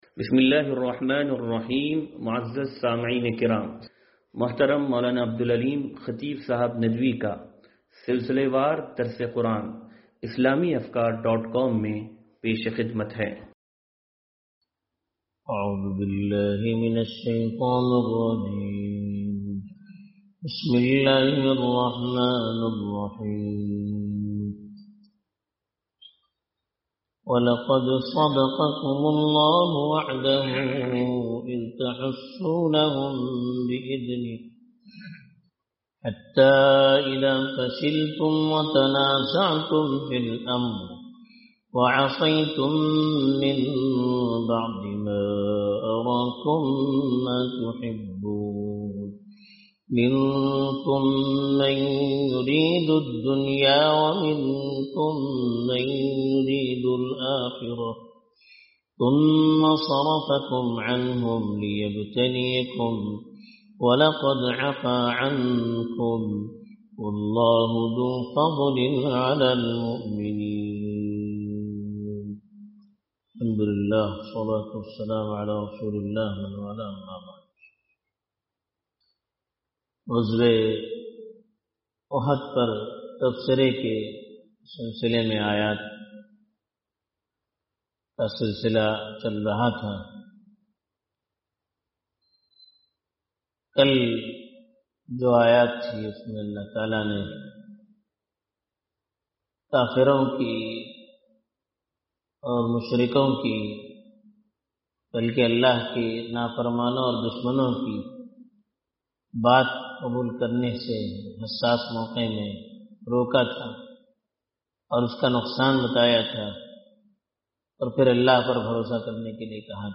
درس قرآن نمبر 0295